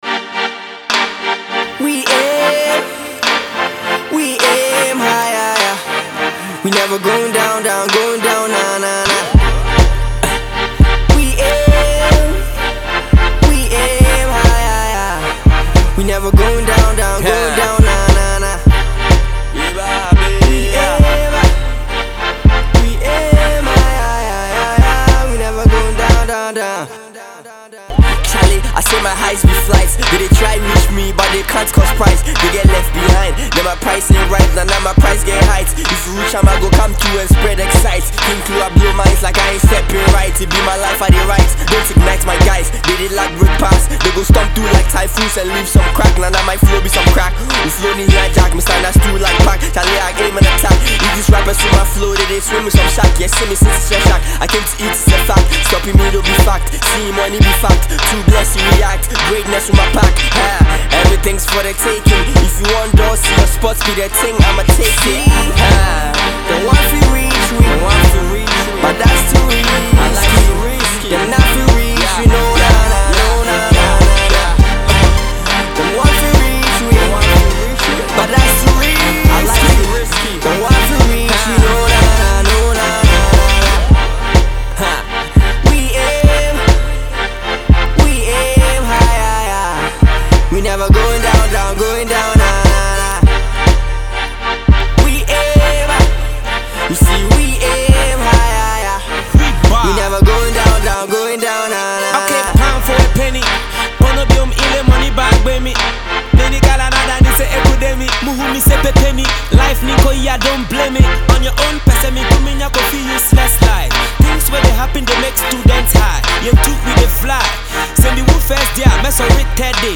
Hip-Hop
Rap song